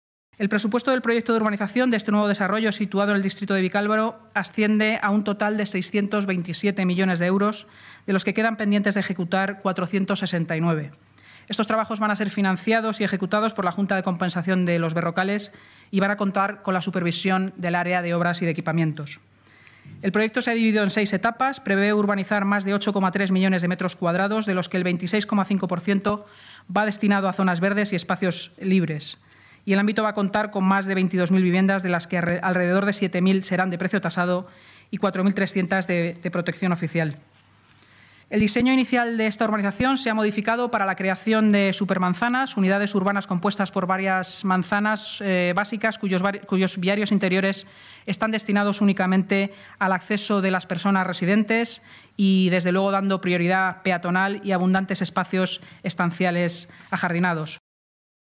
Nueva ventana:Inmaculada Sanz, portavoz Gobierno municipal